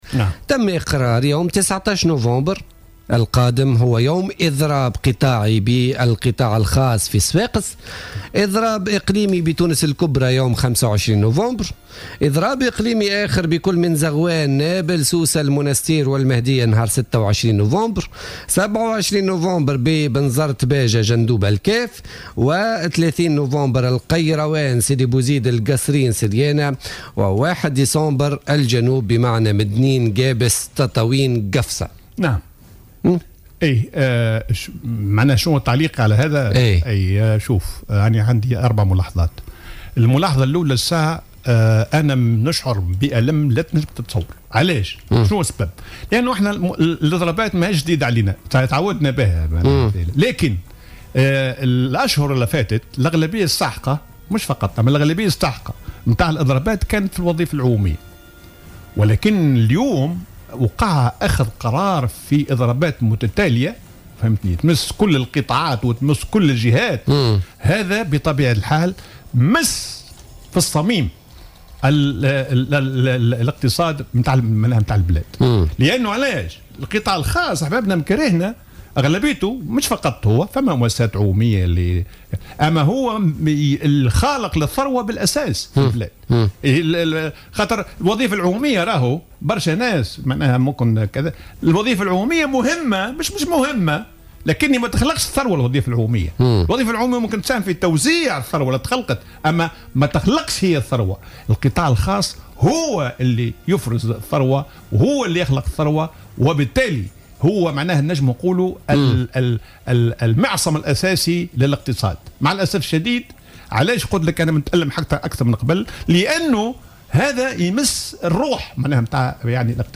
وقال الديماسي في مداخلة له اليوم في برنامج "بوليتيكا" إن إقرار إضرابات متتالية في القطاع الخاص سيمس مختلف القطاعات و سيكون له تبعات اقتصادية خطيرة وسيئة على المدى القصير والطويل و"سيمس في الصميم الاقتصاد الوطني"، وفق تعبيره.